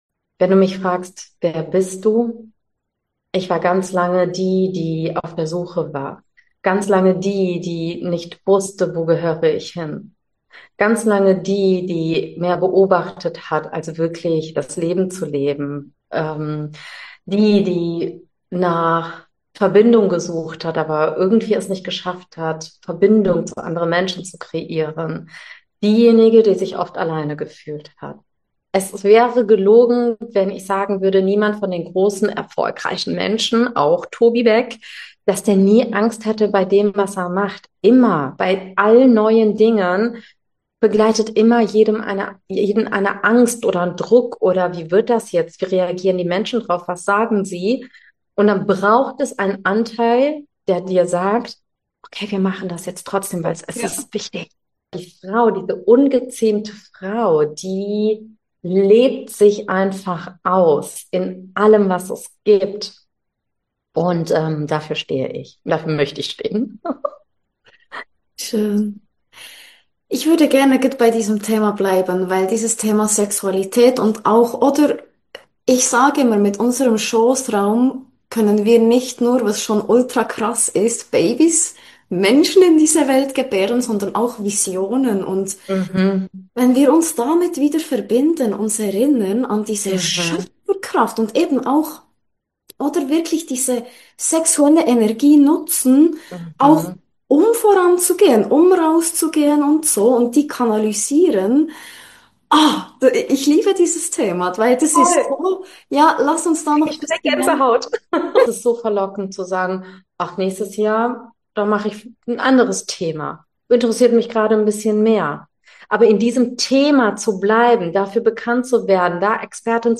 Interview ~ WER BIST DU? Podcast